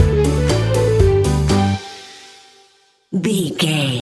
Uplifting
Ionian/Major
Fast
instrumentals
childlike
happy
kids piano